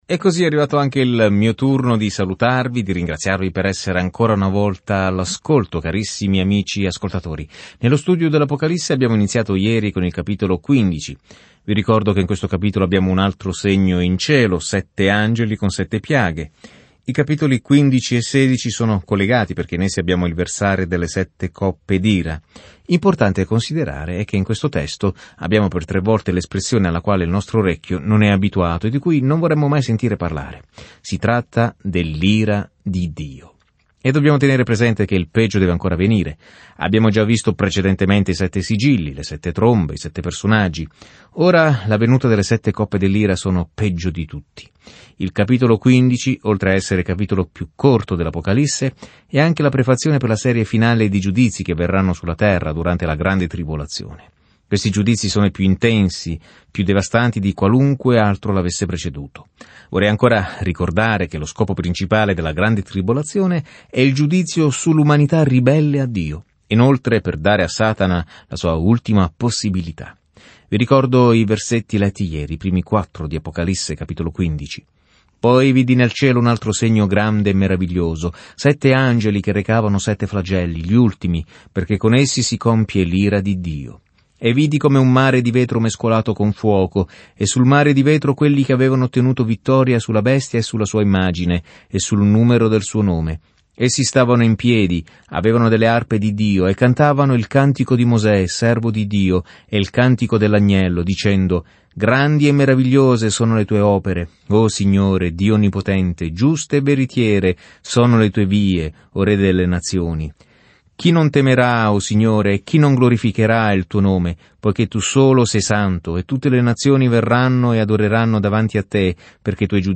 Scrittura Apocalisse di Giovanni 15:5-8 Apocalisse di Giovanni 16:1-2 Giorno 52 Inizia questo Piano Giorno 54 Riguardo questo Piano L’Apocalisse registra la fine dell’ampia linea temporale della storia con l’immagine di come il male verrà finalmente affrontato e il Signore Gesù Cristo governerà con ogni autorità, potere, bellezza e gloria. Viaggia ogni giorno attraverso l'Apocalisse mentre ascolti lo studio audio e leggi versetti selezionati della parola di Dio.